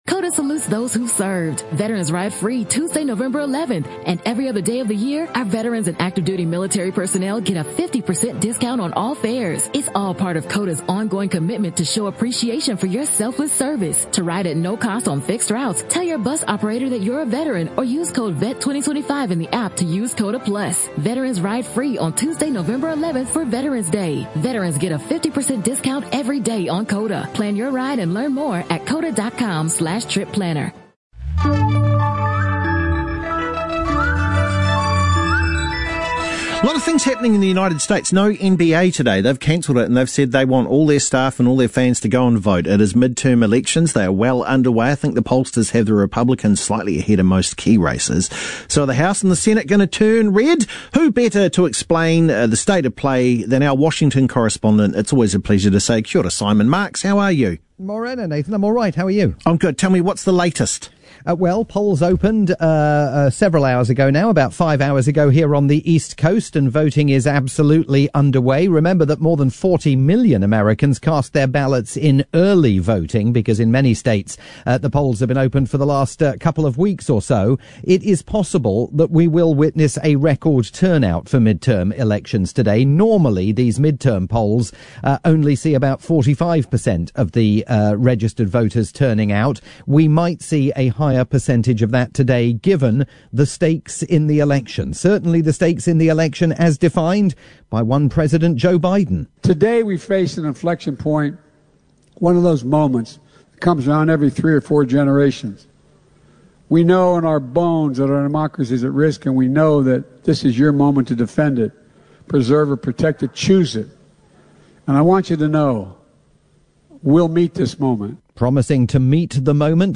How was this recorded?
live update on the midterms for Radio New Zealand's "FirstUp"